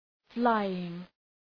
{‘flaııŋ}